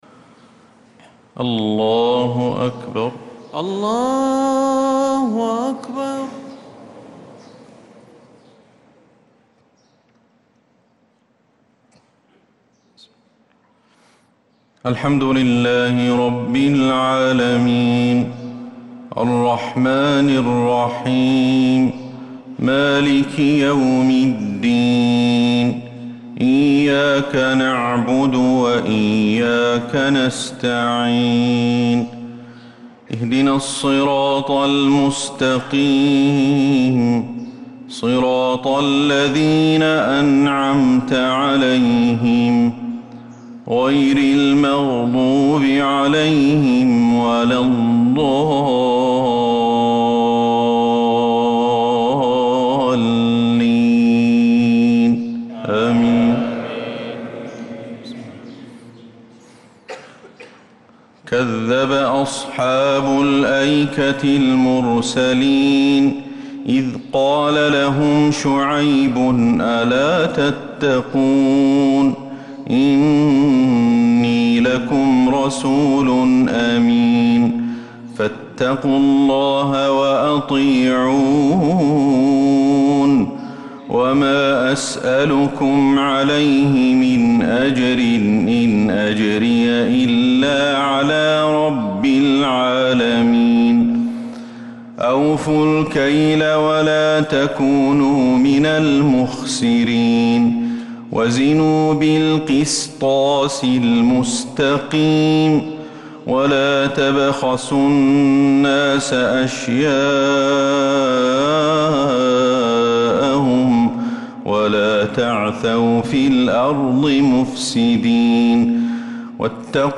صلاة الفجر للقارئ أحمد الحذيفي 27 ذو القعدة 1445 هـ
تِلَاوَات الْحَرَمَيْن .